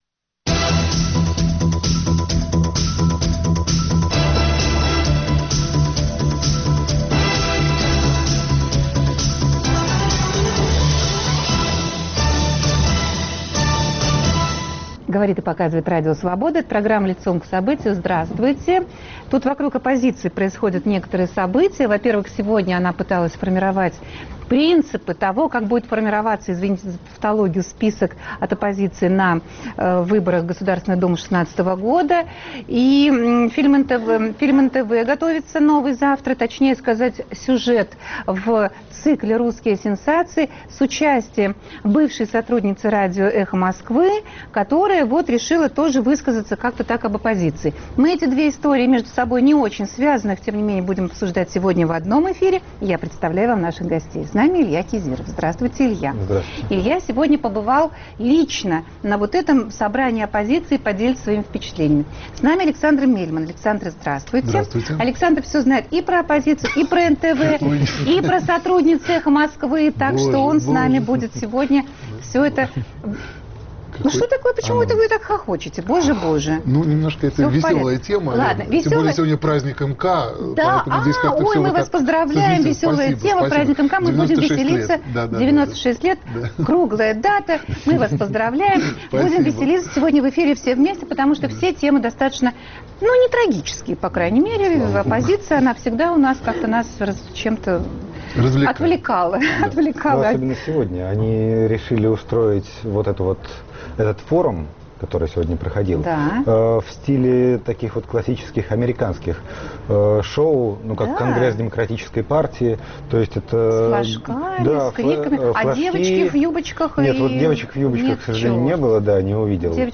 Оппозиция формирует список на выборах в Думу. Канал НТВ формирует передачу об оппозиции с участием сотрудницы "Эха Москвы". В гостях: журналисты